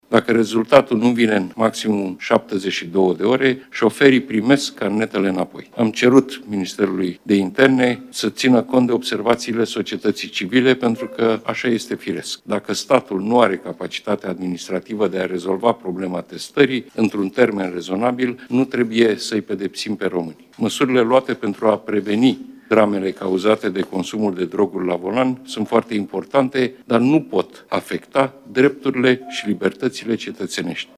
Ordonanța de Urgență 84 privind siguranța rutieră va fi modificată, astfel încât șoferii care ies pozitivi la testarea pentru alcool sau droguri și cei care refuză testarea își vor primi permisul înapoi în cel mult 72 de ore dacă analizele de sânge nu sunt gata în acest interval, a declarat premierul Marcel Ciolacu, joi, 11 iulie, la începutul ședinței de Guvern.